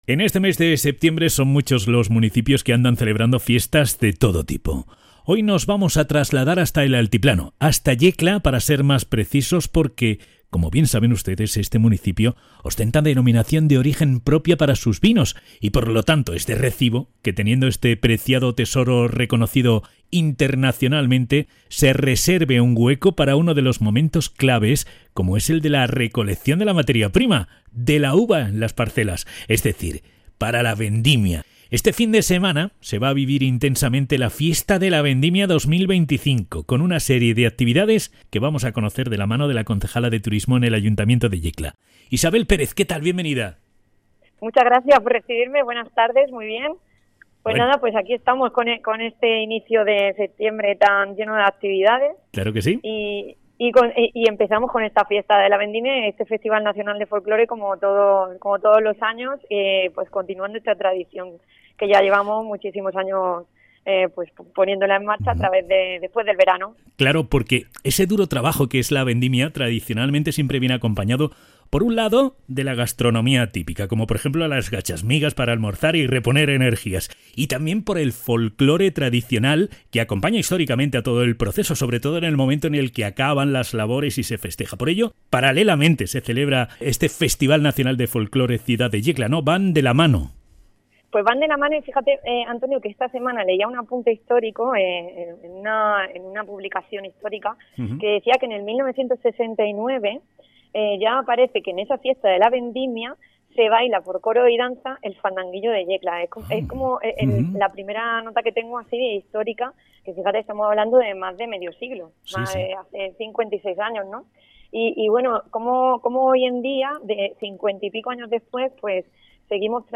Entrevista a Isabel Pérez, concejala de Turismo de Yecla (Fiesta de la Vendimia y Festival Nacional de Folclore y visitas guiadas)